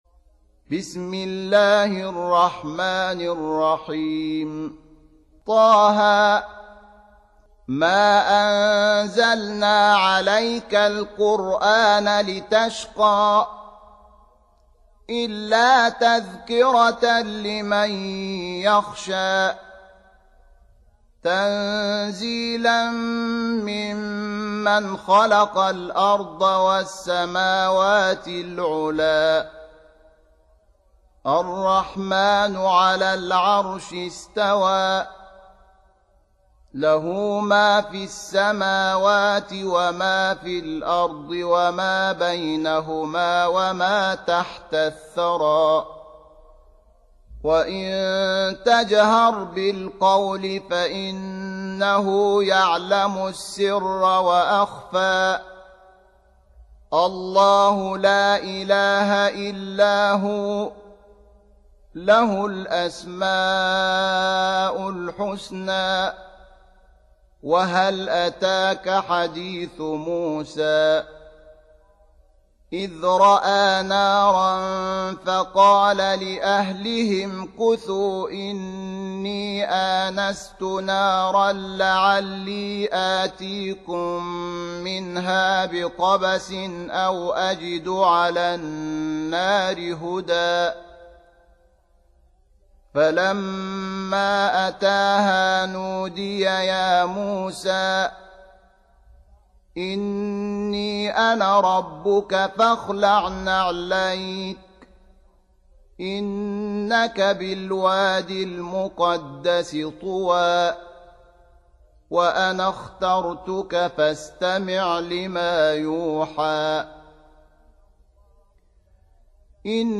Surah Sequence تتابع السورة Download Surah حمّل السورة Reciting Murattalah Audio for 20. Surah T�H�. سورة طه N.B *Surah Includes Al-Basmalah Reciters Sequents تتابع التلاوات Reciters Repeats تكرار التلاوات